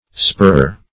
spurrer - definition of spurrer - synonyms, pronunciation, spelling from Free Dictionary Search Result for " spurrer" : The Collaborative International Dictionary of English v.0.48: Spurrer \Spur"rer\ (sp[^u]r"r[~e]r), n. One who spurs.